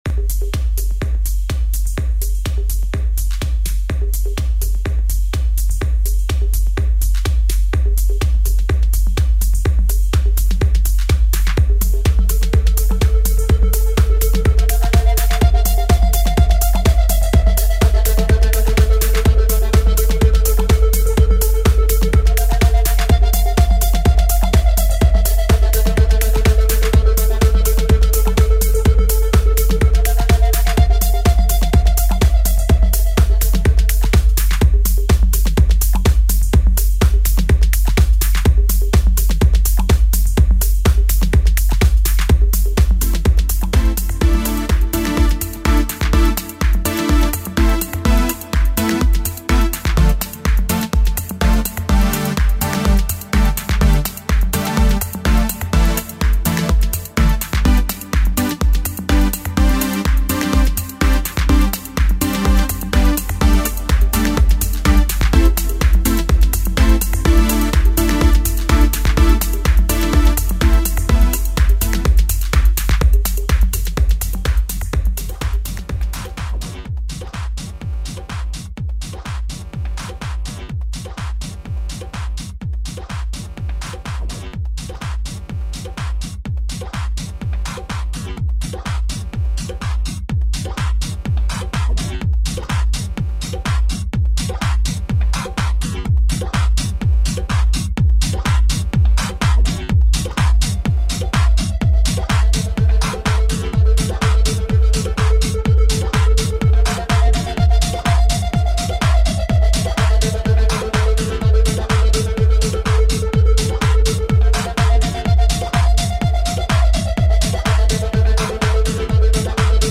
Home > Music > Electronic > Dreamy > Running > Chasing